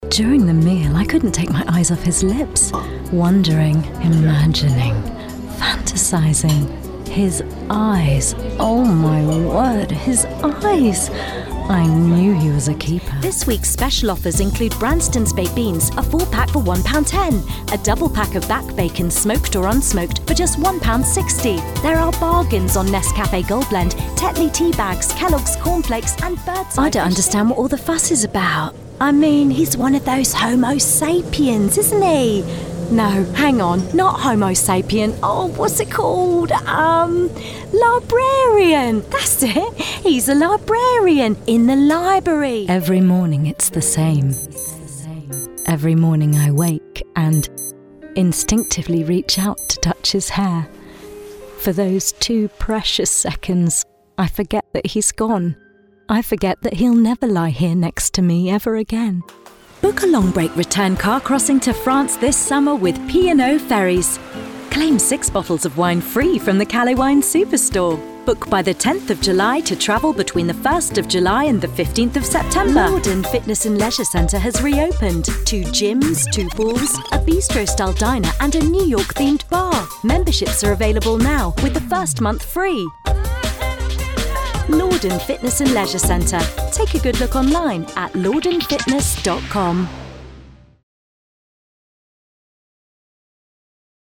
Professional British Voiceover Artist & live event Voice of God with a clear, bright and reassuring voice
Sprechprobe: Werbung (Muttersprache):
She has a naturally modern RP accent and plays in the Teens - 30s age range.